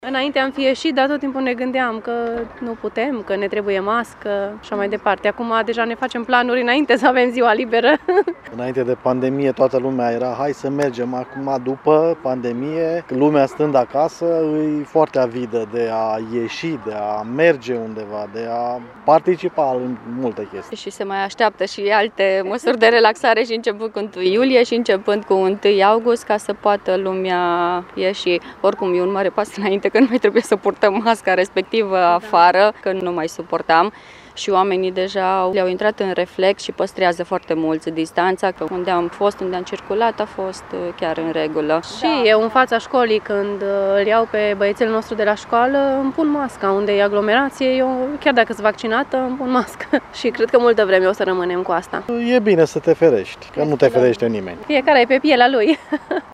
Vox-parinti-1-iunie.mp3